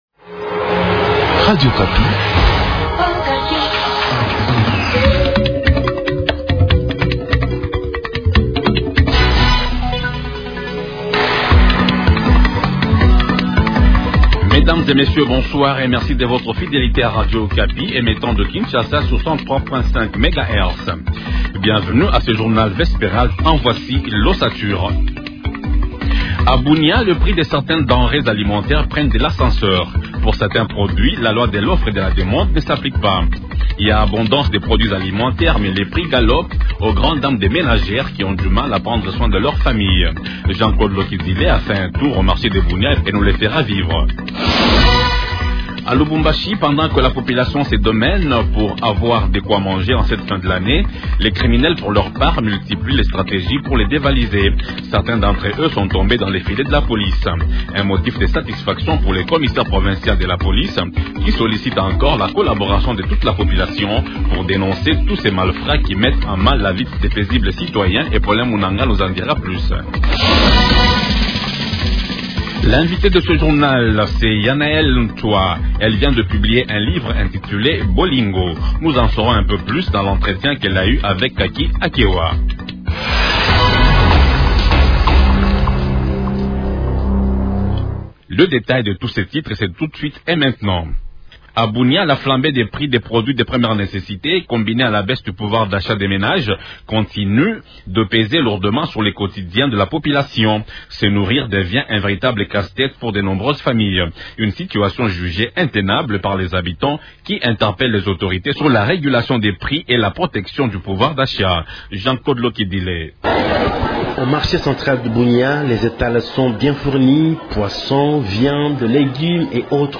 Journal du soir
Les titres du Journal français de 18h :